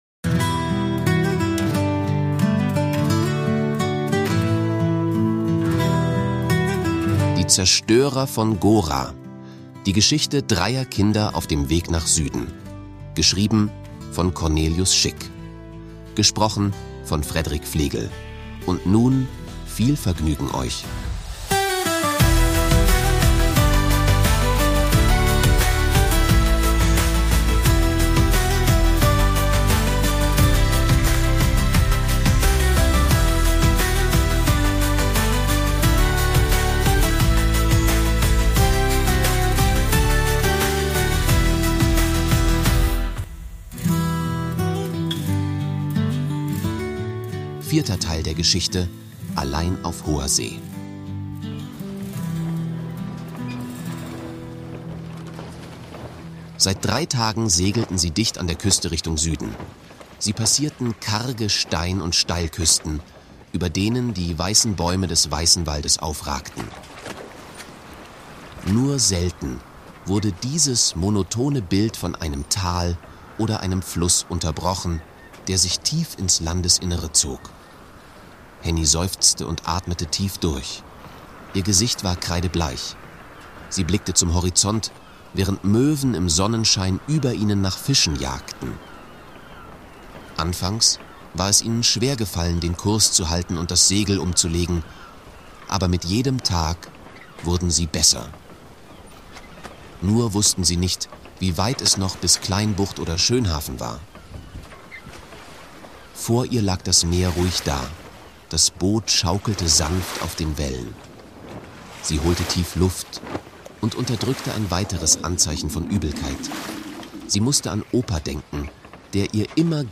Folge 4: Allein auf hoher See Fantasy-Hörbuch mit Sounddesign | Ab 12 Jahren | Deutsch Drei Kinder, ein Boot – und das offene Meer.